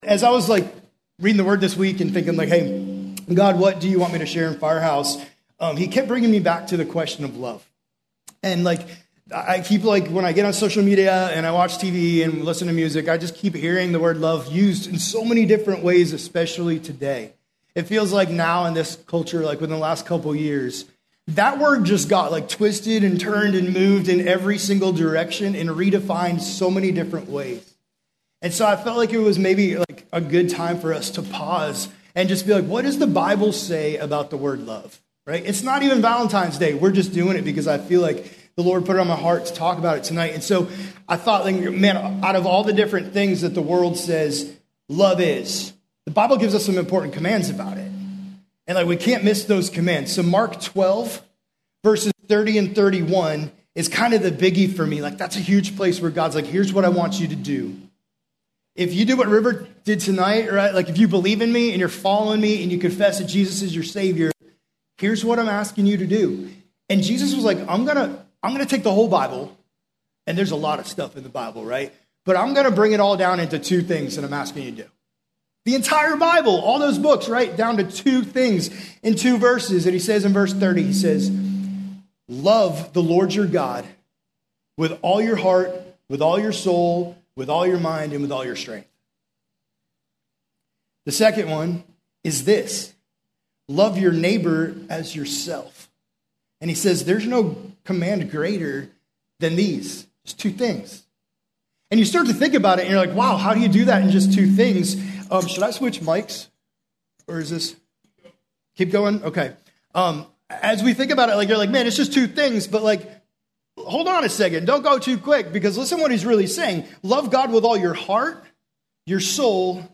Junior High messages from the Firehouse Student Ministry